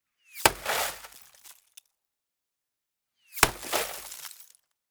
BULLET Impact Plastic LCD TV Screen Shatter Debris 2x.wav